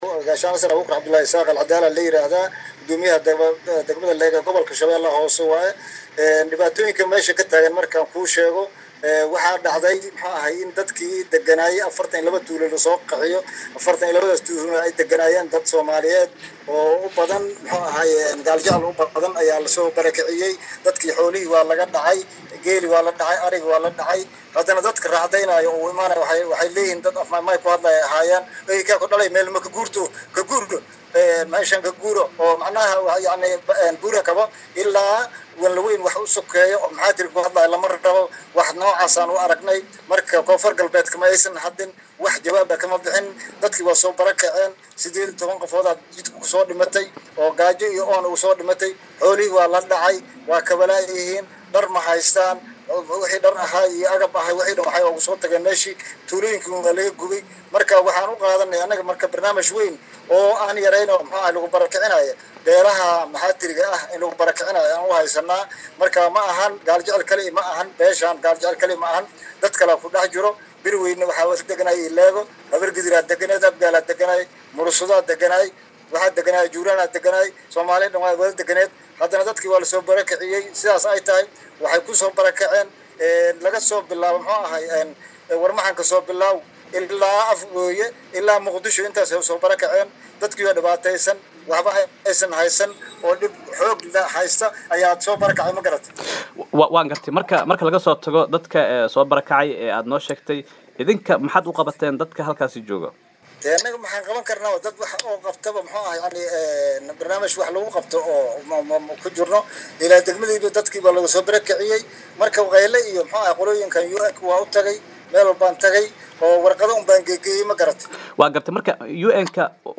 Gudoomiyaha Degmada Leego ee gobolka Shabeelaha Hoose Abuukar Cabdulaahi Isaaq Al Cadaala oo u waramay Warbahinta Aragti Cusub ayaa ka hadlay xaalada Tuulooyin hoostaga degmada Leego ee Gobolka Shabeelaha Hoose .